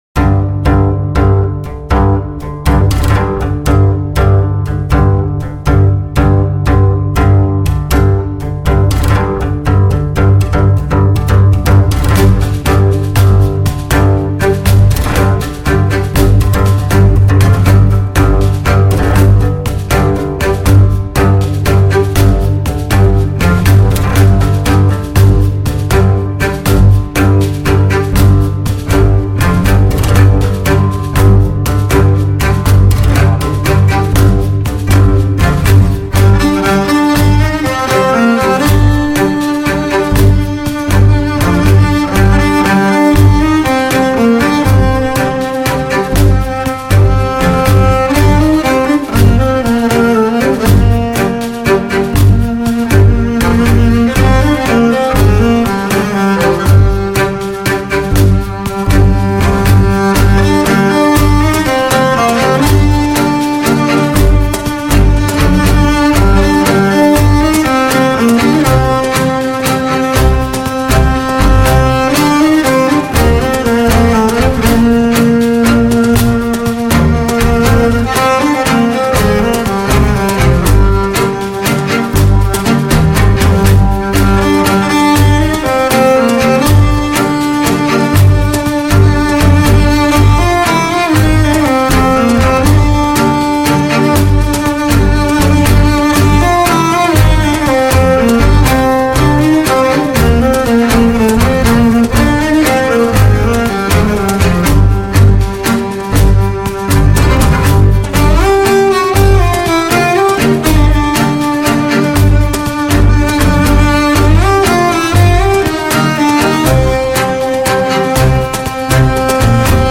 Genre : Jazz, World & Ethnic